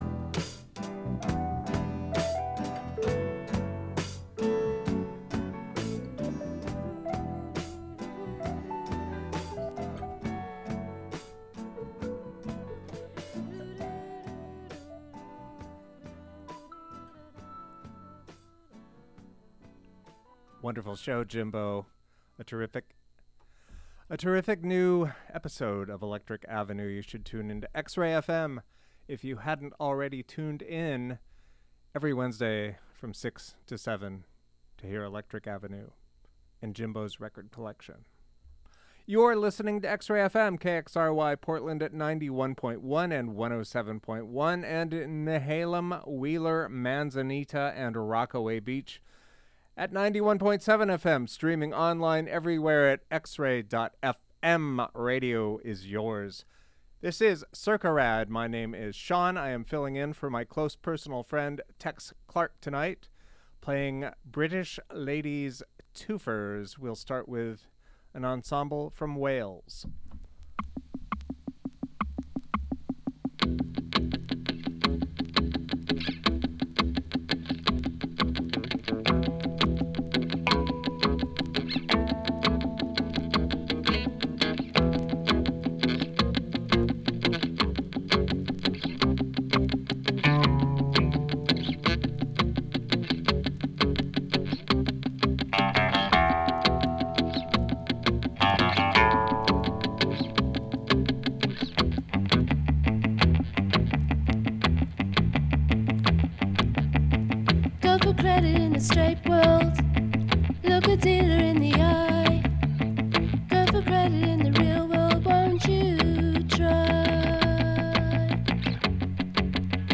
We serve indie rock, global psychedelica, lo-fi, art punk, a particular sort of americana, Portland-centric, international pop, folkishness, and real rock and good radio.